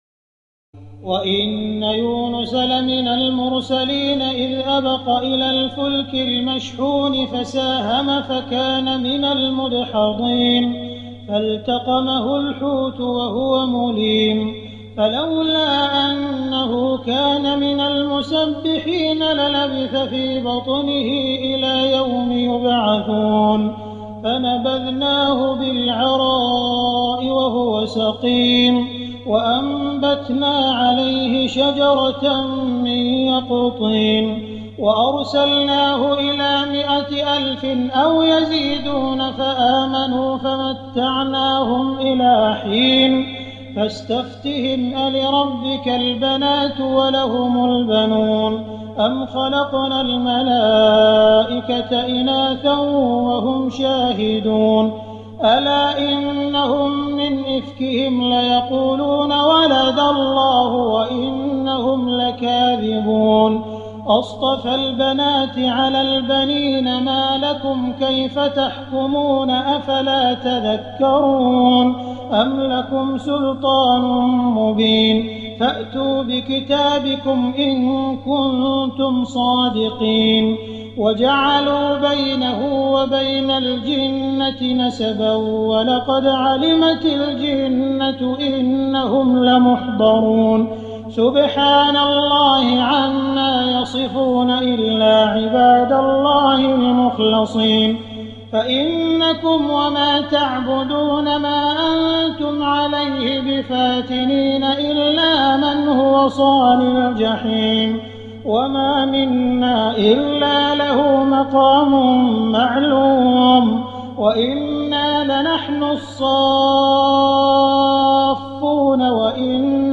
تراويح ليلة 22 رمضان 1419هـ من سور الصافات(139-182) وص و الزمر (1-31) Taraweeh 22 st night Ramadan 1419H from Surah As-Saaffaat and Saad and Az-Zumar > تراويح الحرم المكي عام 1419 🕋 > التراويح - تلاوات الحرمين